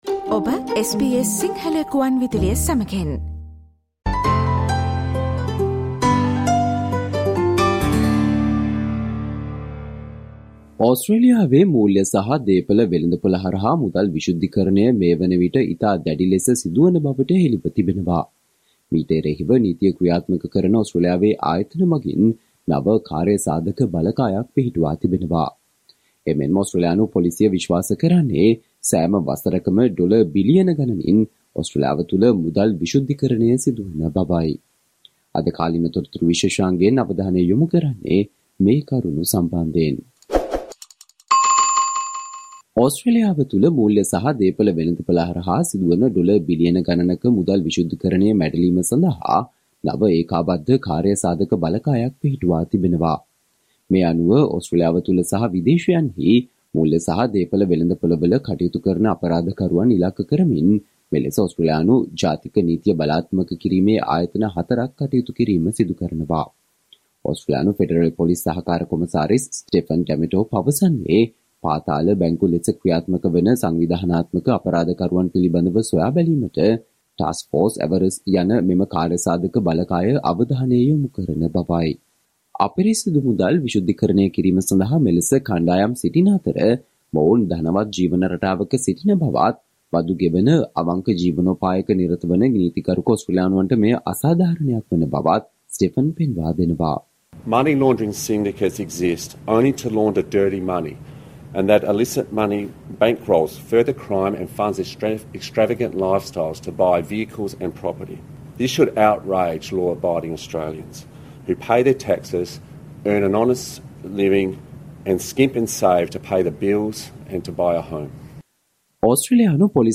Today - 20 March, SBS Sinhala Radio current Affair Feature on Taskforce to pursue dirty money